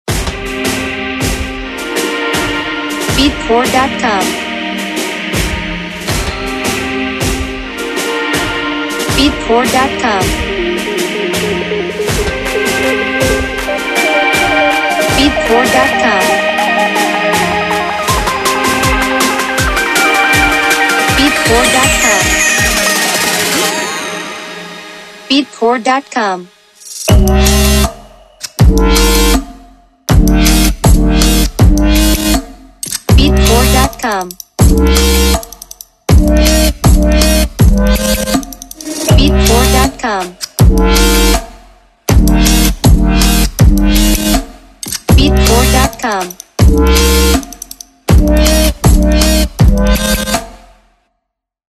Genre: Chill Trap Mood: Funny Weird
Time Signature: 4/4
Instruments: Synthesizer